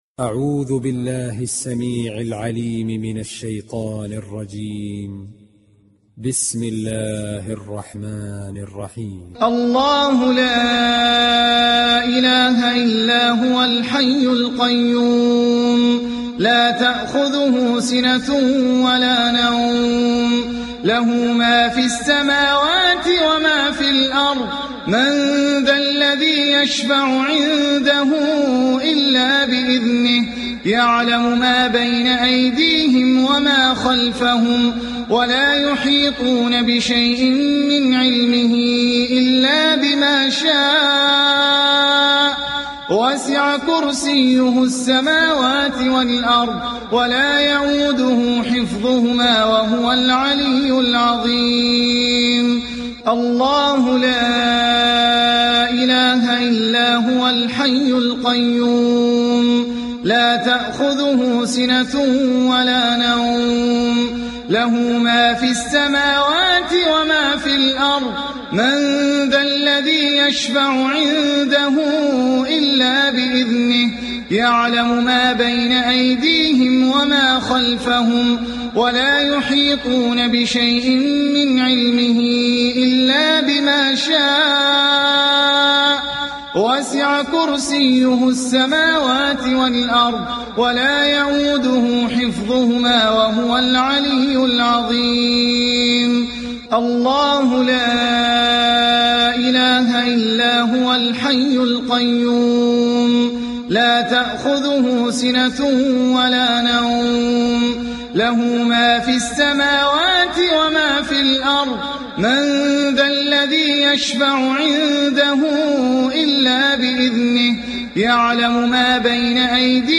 بصوت الشيخ احمد العجمي